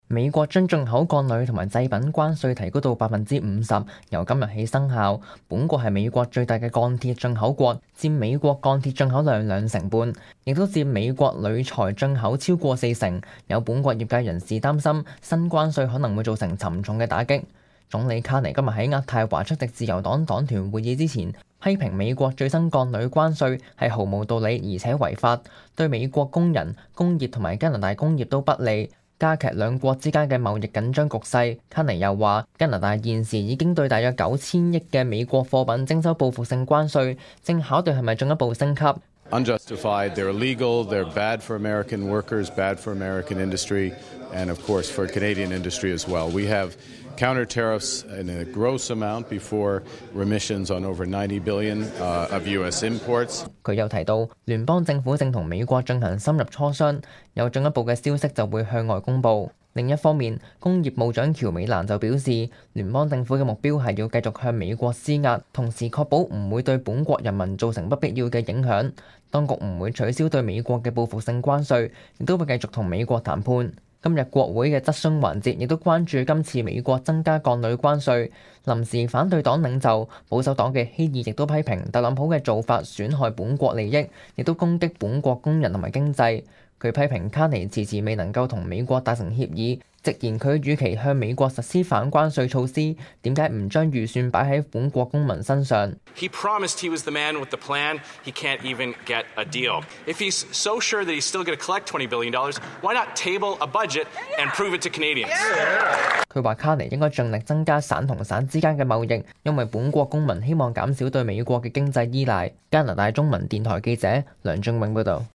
報道